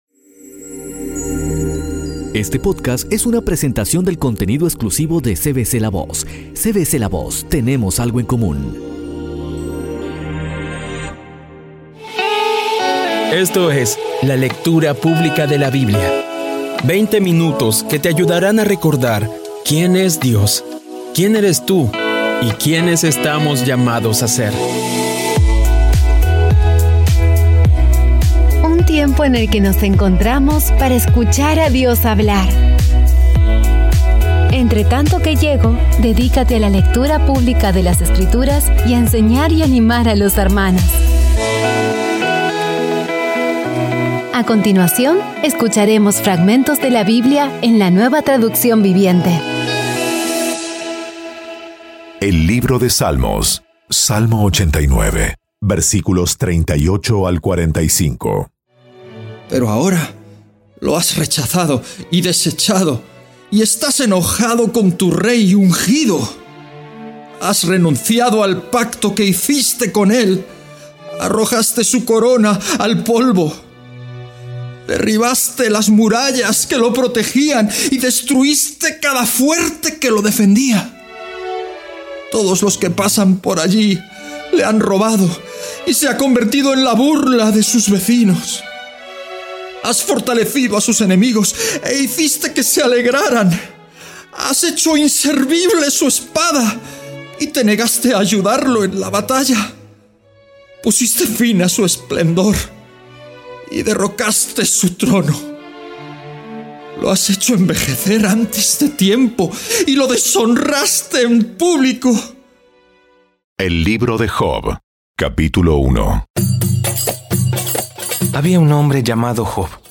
Audio Biblia Dramatizada Episodio 219
Sigue usando esta maravillosa herramienta para crecer en tu jornada espiritual y acercarte más a Dios. Poco a poco y con las maravillosas voces actuadas de los protagonistas vas degustando las palabras de esa guía que Dios n...